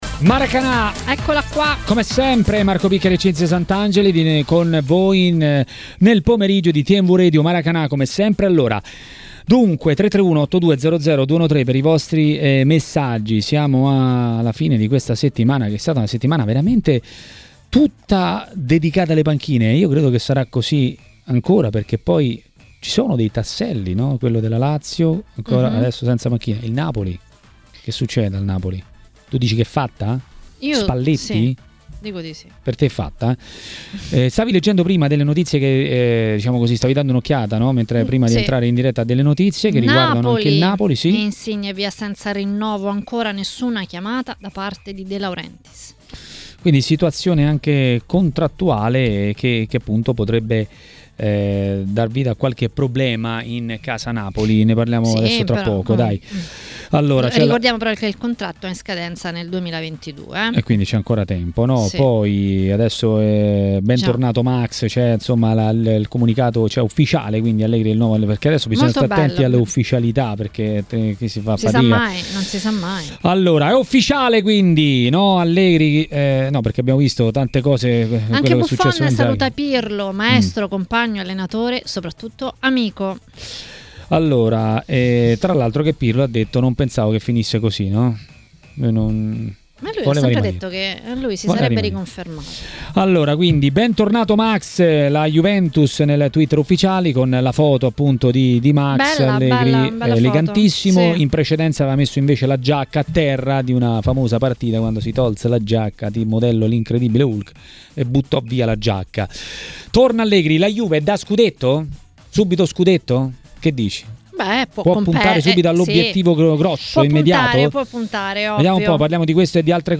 L'ex bomber e tecnico Bruno Giordano a Maracanà, trasmissione di TMW Radio, ha parlato dei casi del momento.